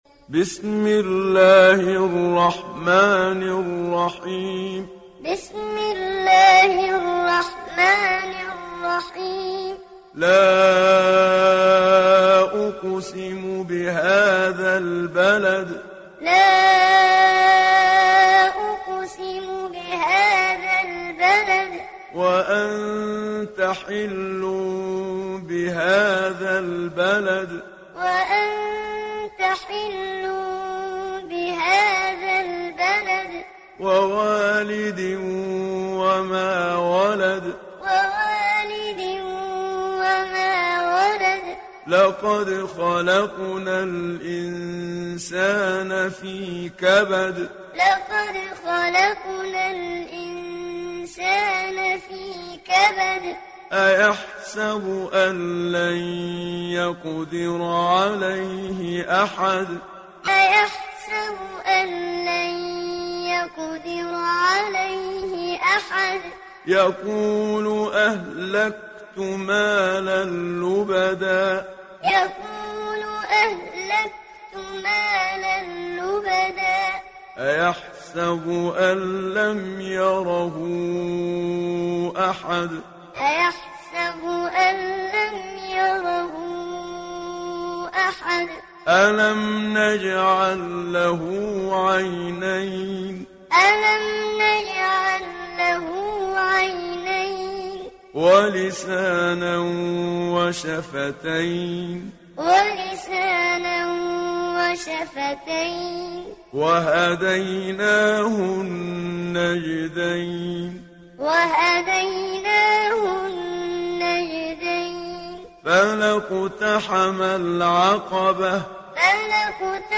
تحميل سورة البلد محمد صديق المنشاوي معلم